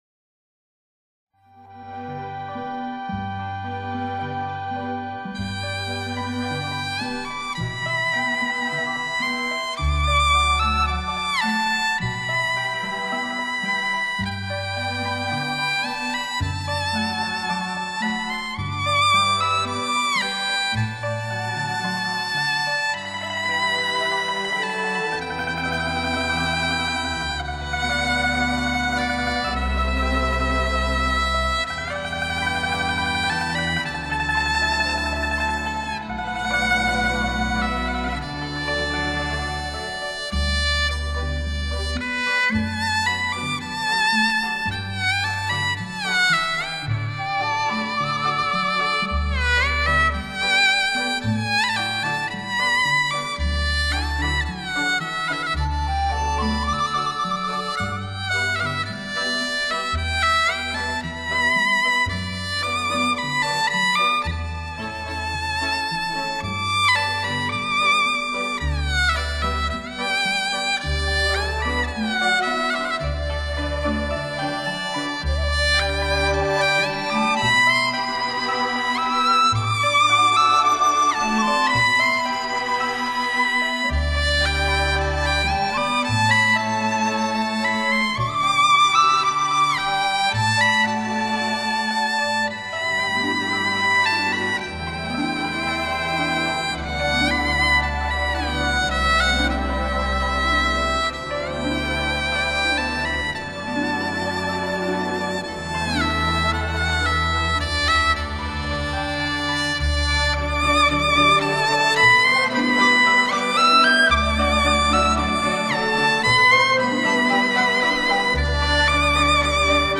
在保持该曲原有风采的基调上，更增添了纯正的中国民族特色。
频响丰富、音场宽阔、声部清晰且具高保真，实为当 今音响之杰作，它无疑具有颇高的欣赏和收藏价值 。